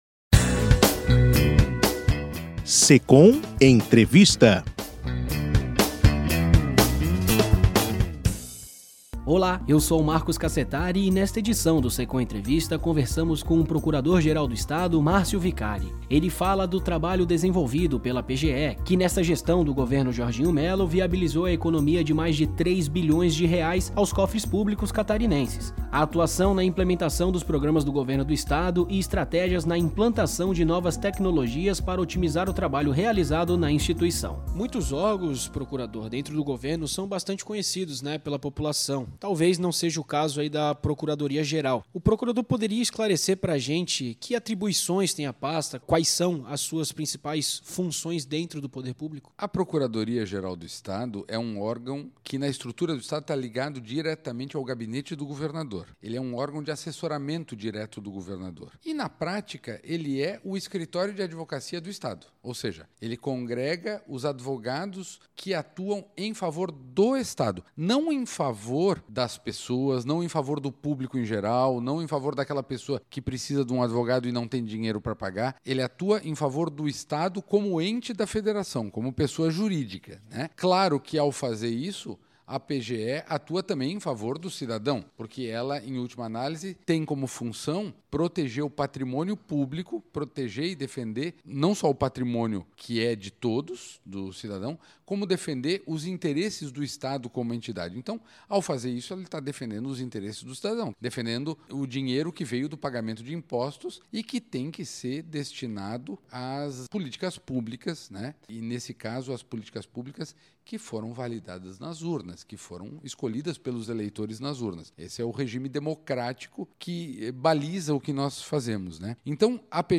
Nesta edição do SECOM Entrevista, conversamos com o Procurador-Geral do estado, Márcio Viccari. Ele fala do trabalho desenvolvido pela PGE que nesta gestão do Governo Jorginho Mello, viabilizou a economia de mais de R$3 bilhões aos cofres públicos catarinenses, a atuação na implementação dos programas do Governo do Estado e estratégias na implantação de novas tecnologias para otimizar o trabalho realizado na instituição.
SECOM-Secom-Entrevista-Procurador-Geral-do-Estado-Marcio-Vicari.mp3